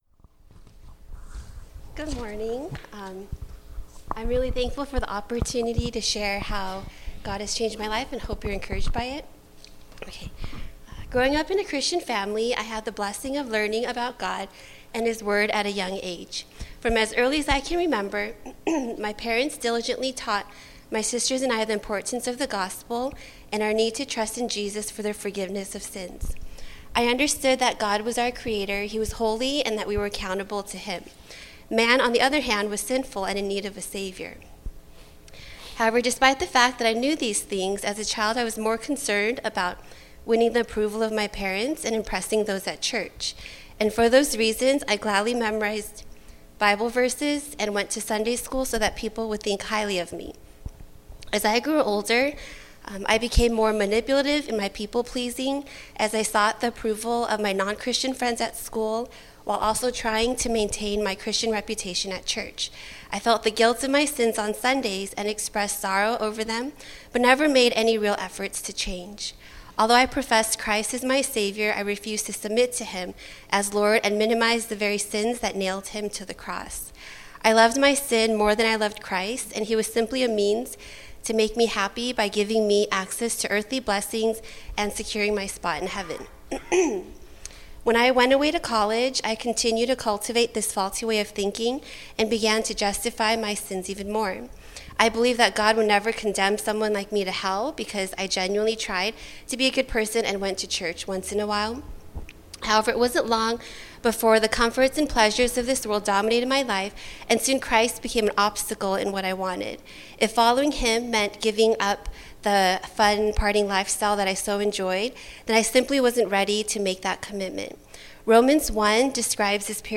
September 27, 2015 (Sunday Service)